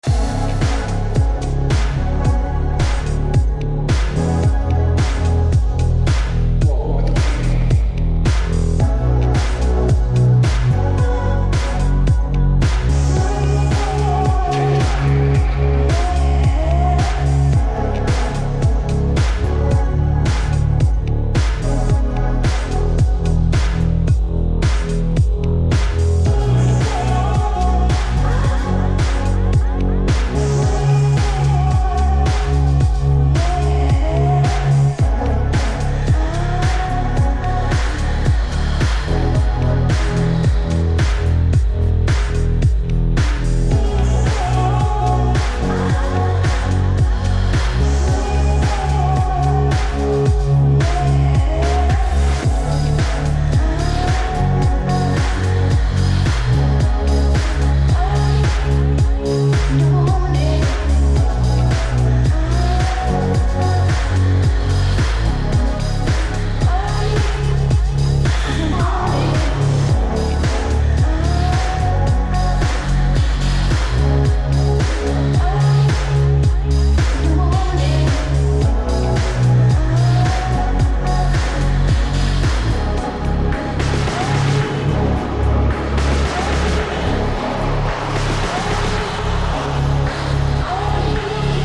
Strong nu disco/House Ep that comes in a batch of 5 tracks.
Disco House